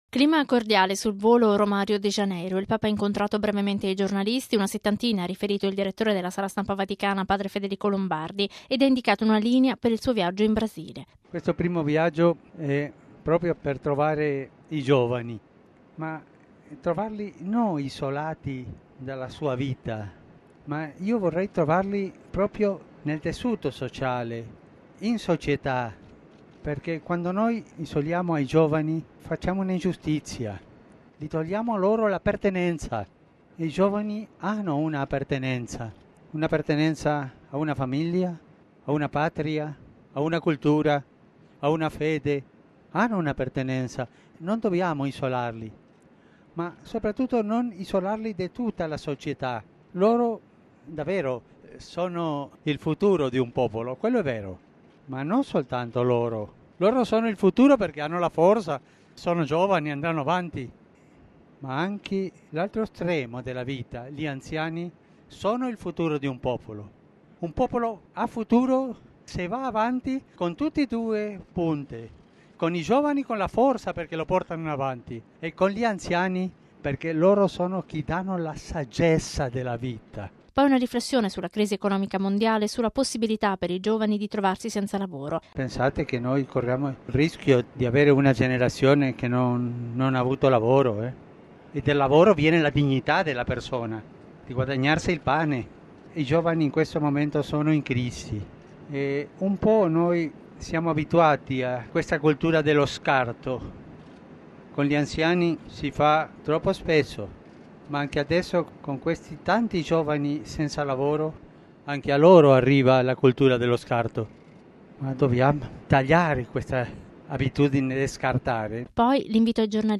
Clima cordiale sul volo Roma – Rio de Janeiro.